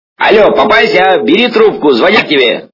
При прослушивании Миша Галустян - Алле, папася, бери трубку качество понижено и присутствуют гудки.